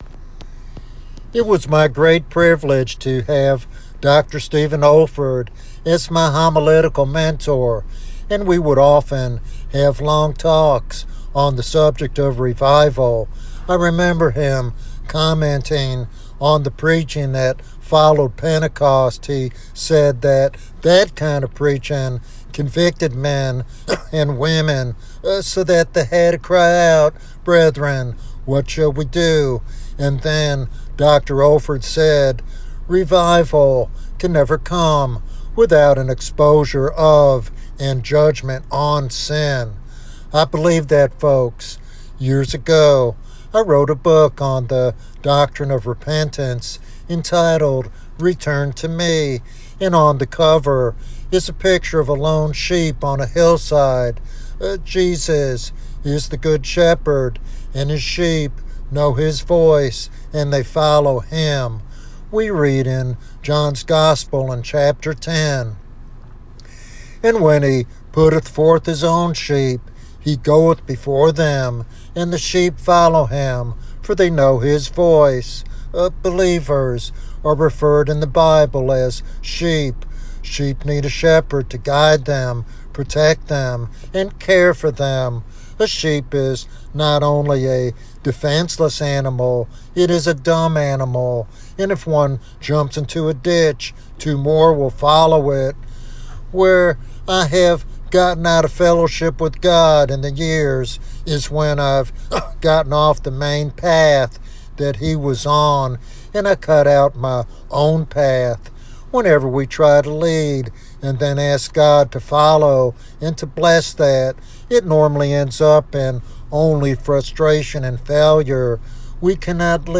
This sermon calls believers to return to God’s leadership and seek a fresh outpouring of His Spirit in these challenging times.